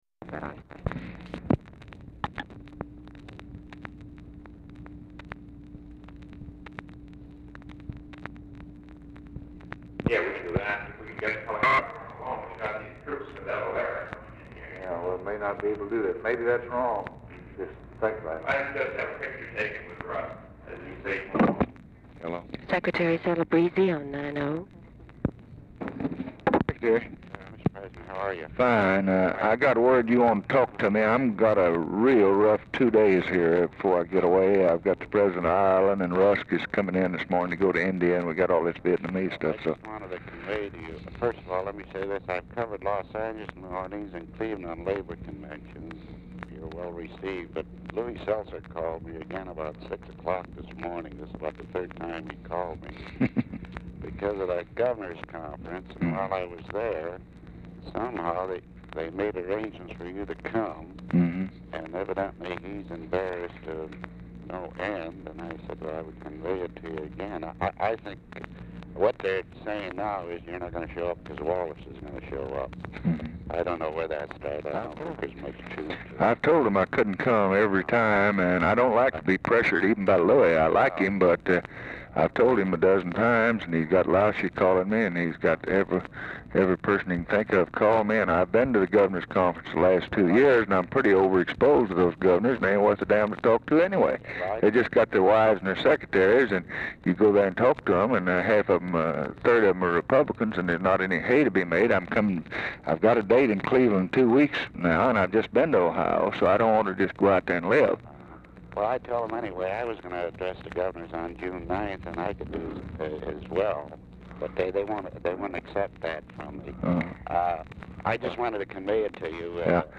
Telephone conversation # 3514, sound recording, LBJ and ANTHONY CELEBREZZE
OFFICE CONVERSATION PRECEDES CALL
Format Dictation belt
Location Of Speaker 1 Oval Office or unknown location